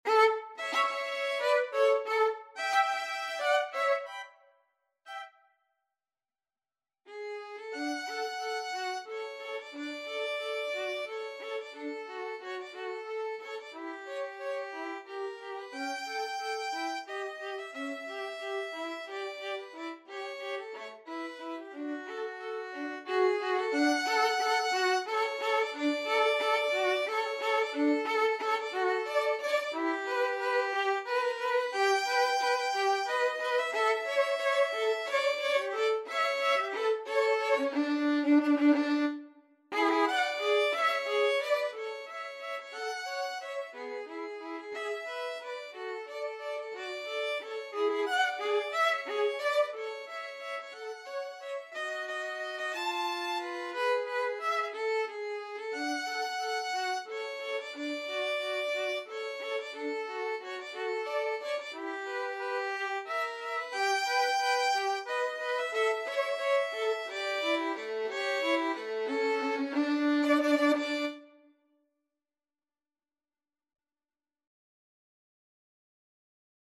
Classical Strauss II,Johann Wiener Blut (Viennese Blood), Waltz Op.354 Violin Duet version
Violin 1Violin 2
3/4 (View more 3/4 Music)
~ = 180 Tempo di Valse
D major (Sounding Pitch) (View more D major Music for Violin Duet )